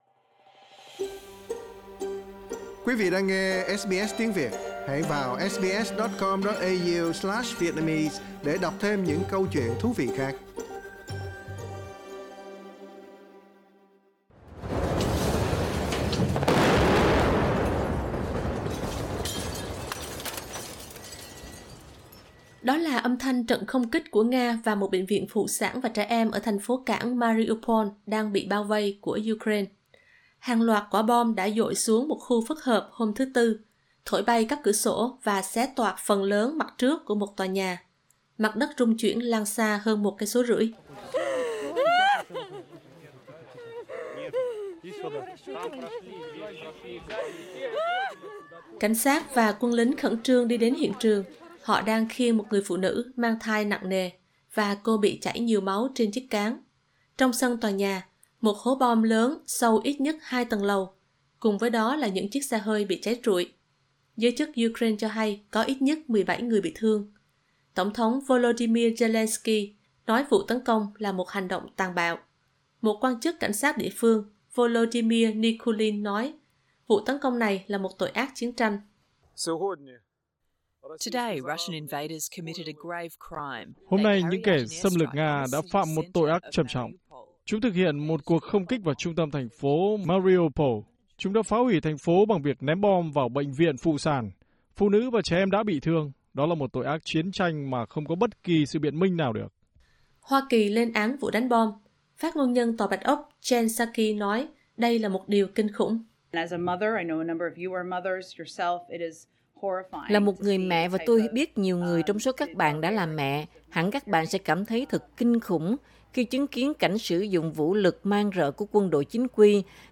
Đó là âm thanh trận không kích của Nga vào một bệnh viện phụ sản và trẻ em ở thành phố cảng Mariupol đang bị bao vây của Ukraine.